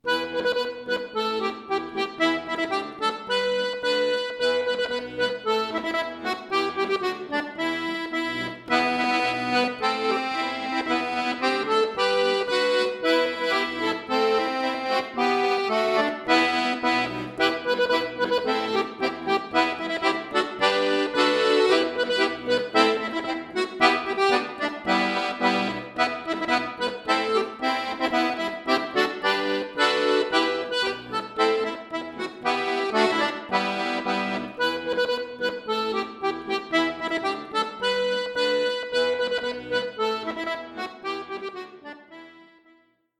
Traditional aus Norwegen, neu arrangiert für Akkordeon solo
Folksong